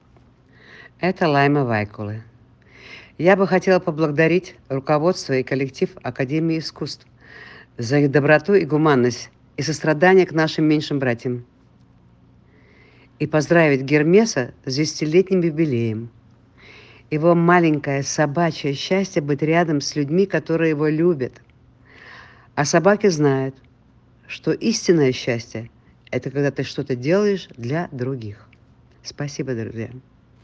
Лайма Вайкуле, певица, актриса - СЛУШАТЬ АУДИОЗАПИСЬ